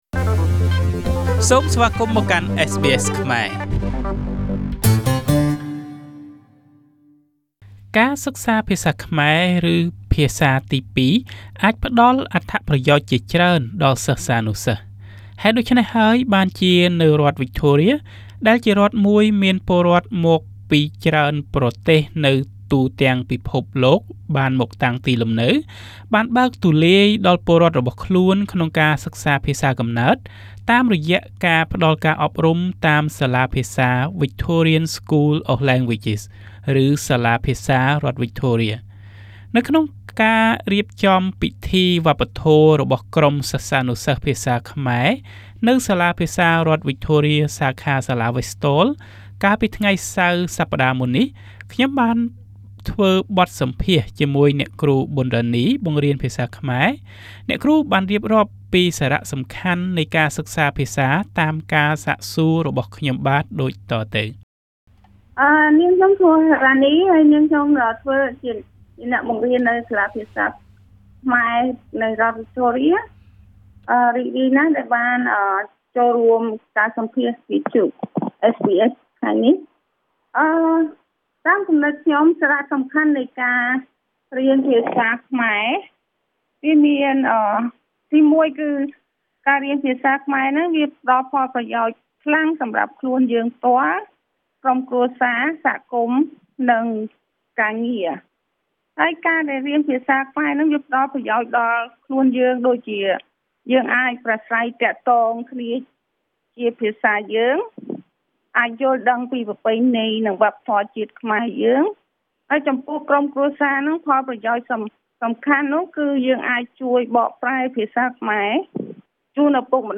During a Culture Day celebration at VSL, I interviewed some Khmer teachers on the importance of studying the language.